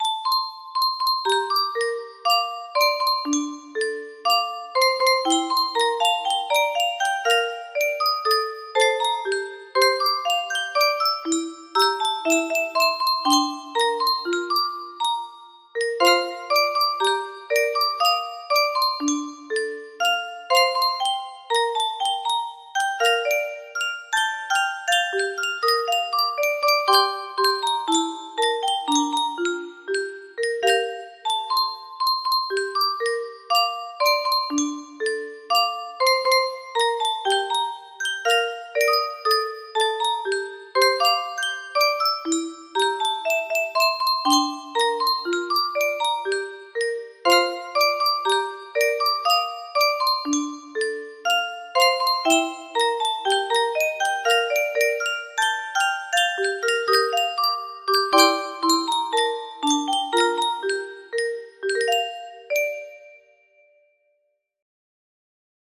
Grand Illusions 30 (F scale)
BPM 120